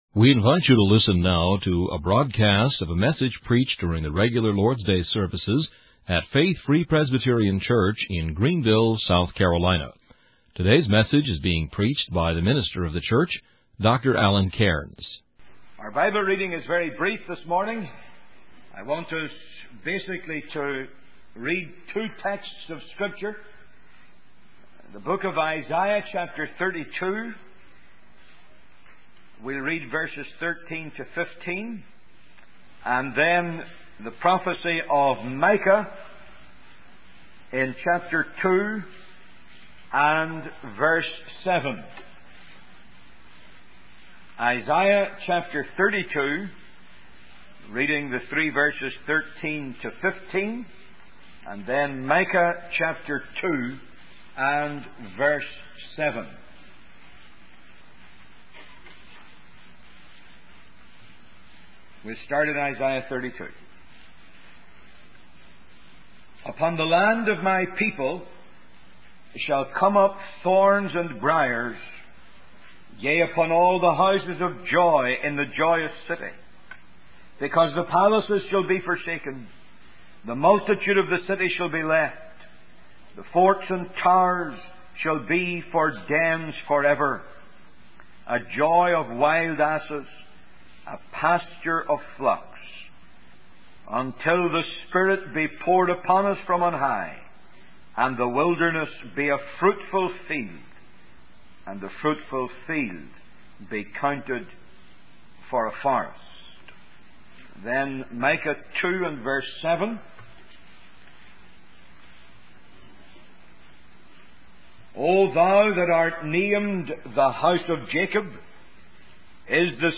In this sermon, the preacher emphasizes the importance of prayer for revival in times of spiritual decline in the Church.